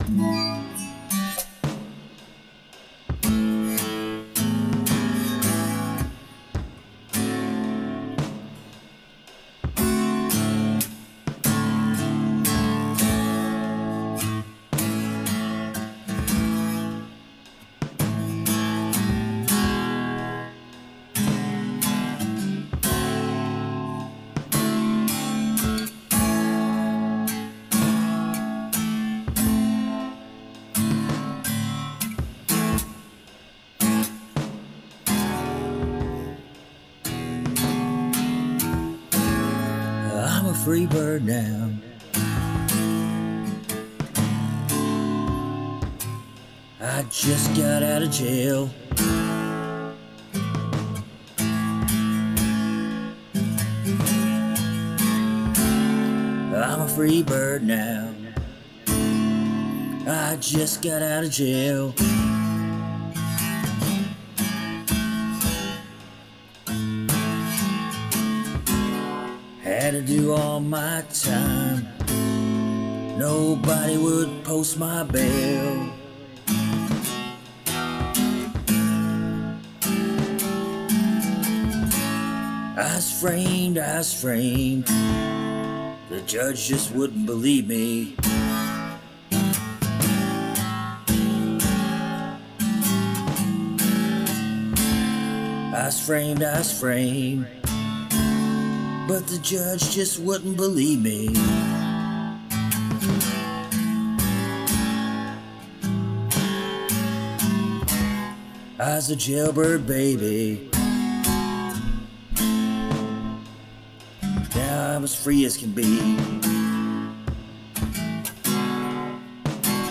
like these blues.